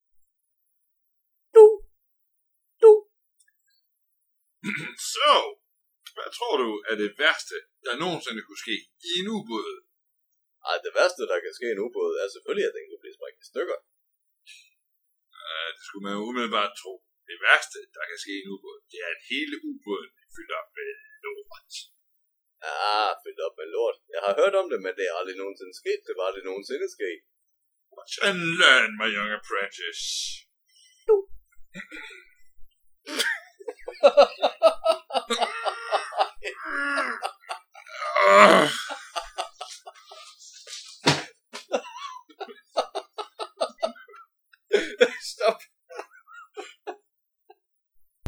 Med ombord på SSBN-742 Sælen var også to tyske officerer fra den tyske Kriegsmarine, der her taler lidt om det værste de har været med til i ubåd.